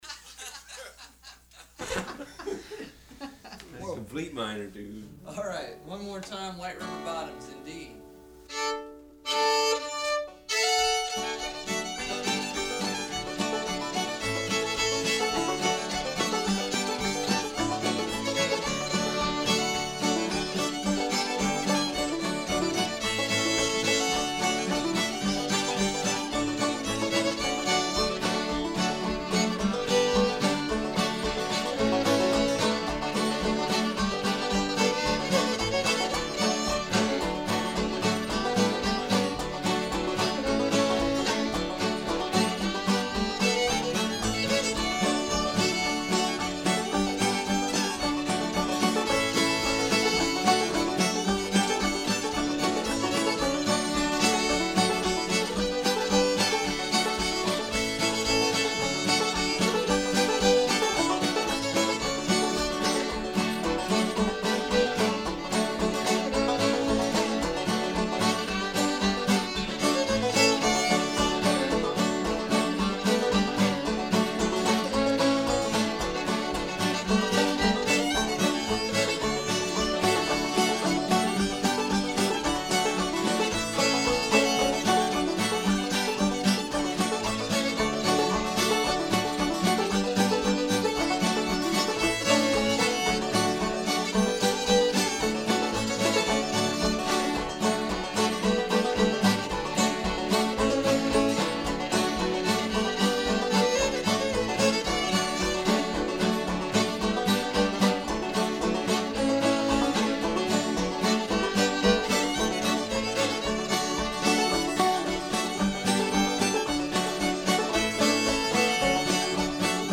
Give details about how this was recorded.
Field Recordings: Fiddlers